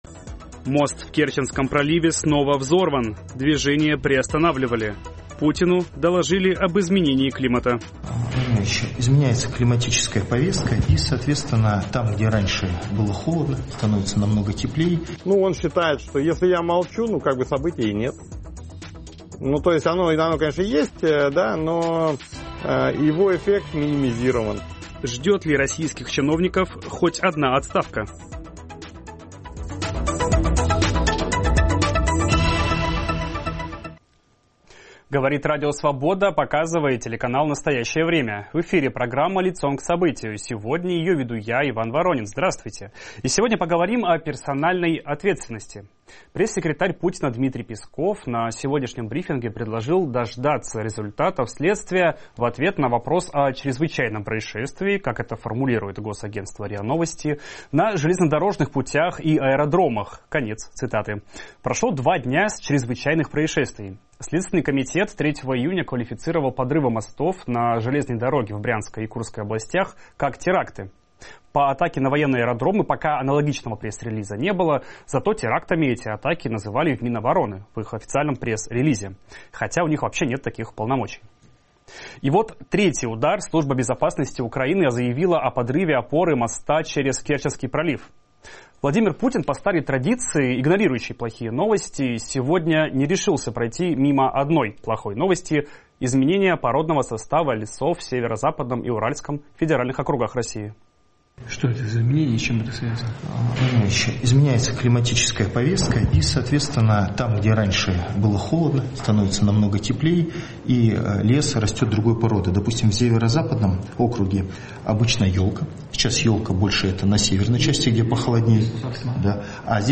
Об этом говорим с политологом Аббасом Галлямовым и политиком, бывшим сотрудником КГБ, Геннадием Гудковым.